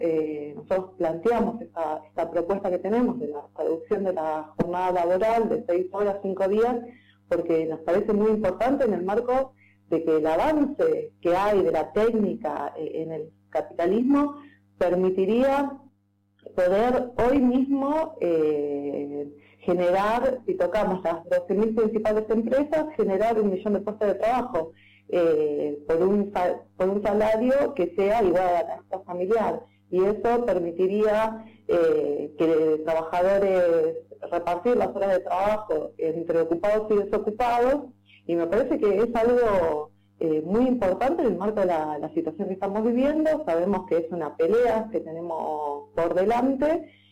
Entrevistada por Cuarto Oscuro (FM La Cuerda 104.5), brindó su postura y adelantó la llegada de su precandidato a vicepresidente Nicolás del Caño, quien arribará a Salta este miércoles para iniciar la recta final de campaña rumbo a las PASO de agosto.